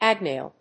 音節ag・nail 発音記号・読み方
/ˈægneɪl(米国英語)/